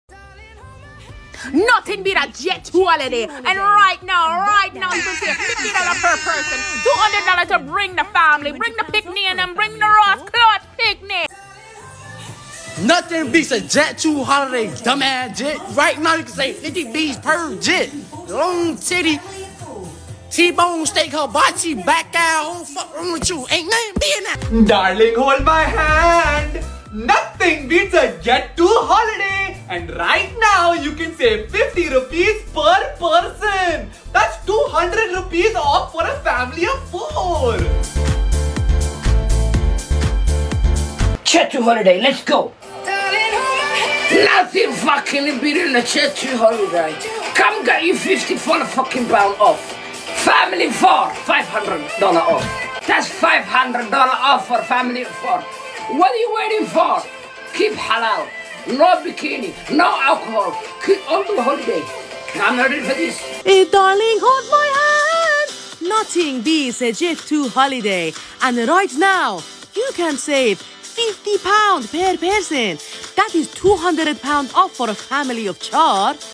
Best JET2HOLIDAY Impression In Different Accents